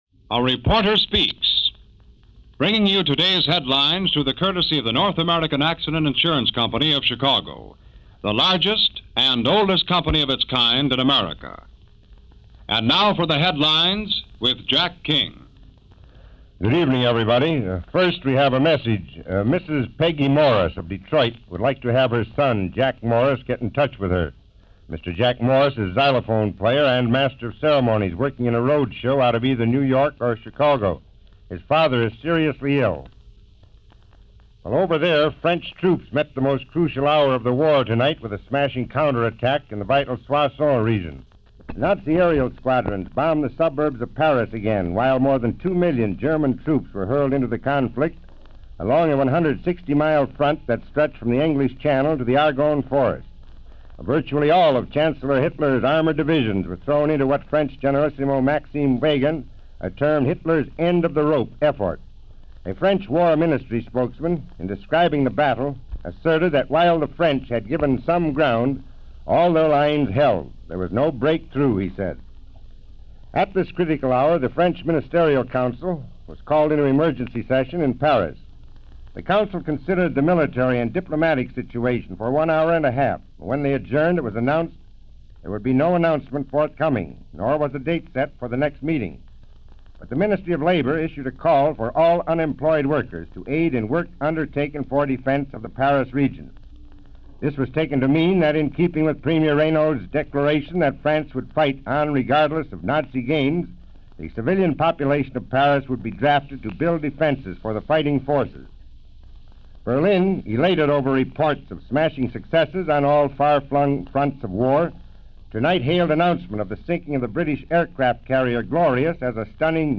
Europe At War: Two Million German Troops Along French Border - June 9, 1940 - News from WJR, Detroit - A Reporter Speaks